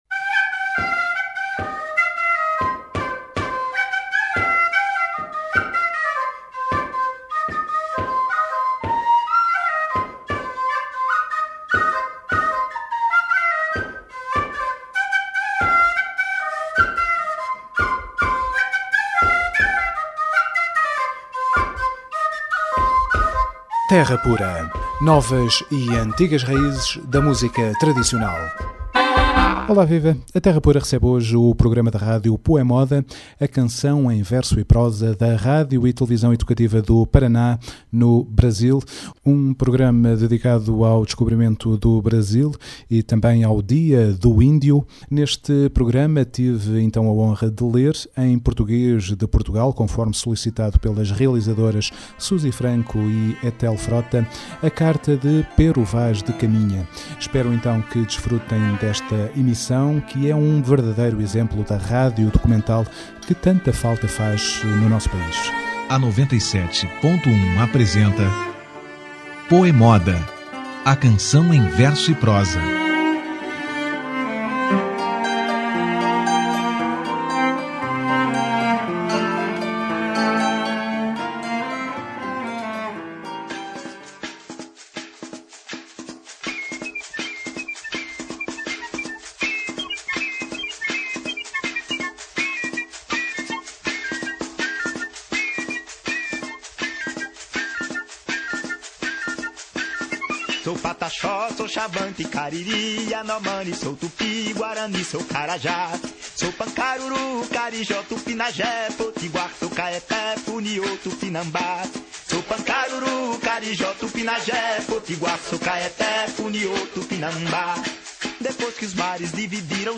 Desfrutem um programa que é um bom exemplo da rádio documental que tanta falta faz por cá.